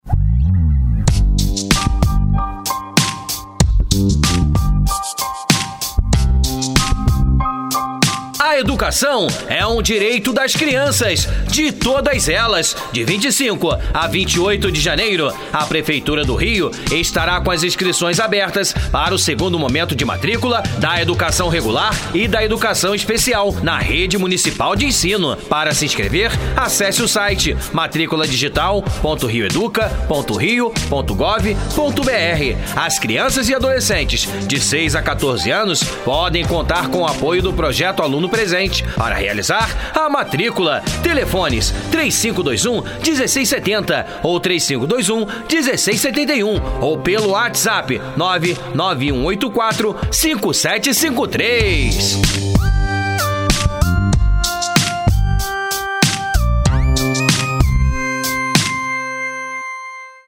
Spot de matrícula